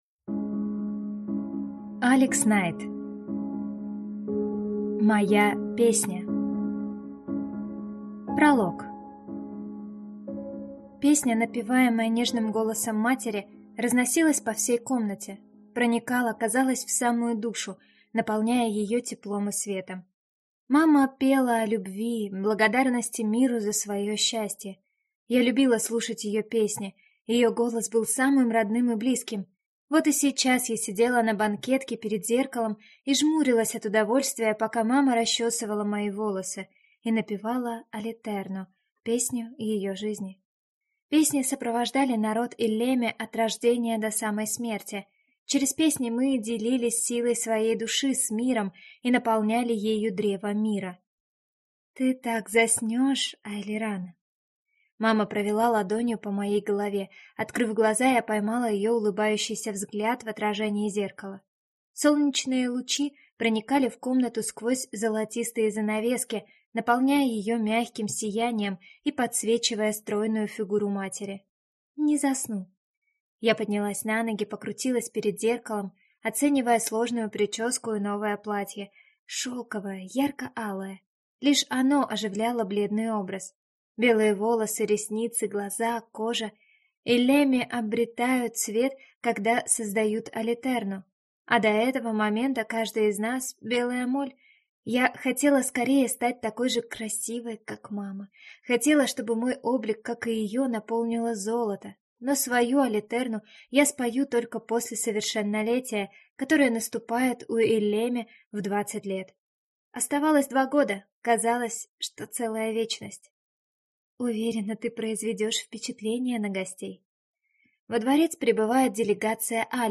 Aудиокнига
Читает аудиокнигу